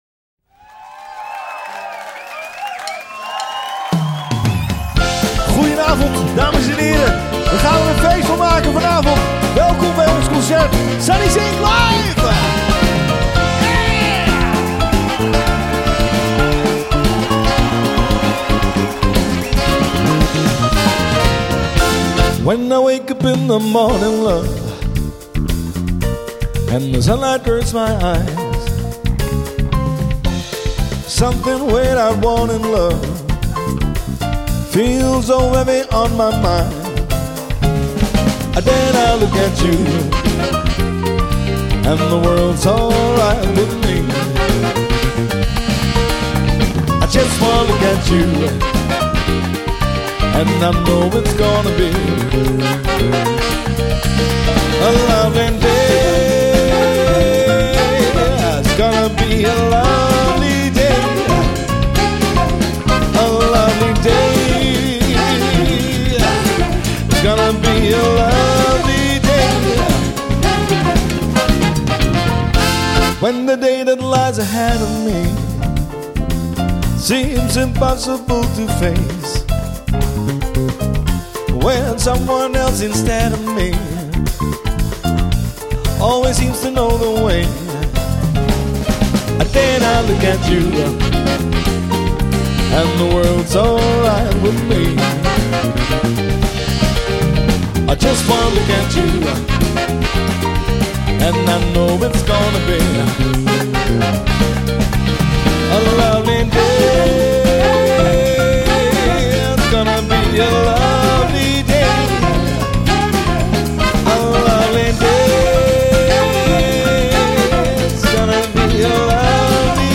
Genre: Party.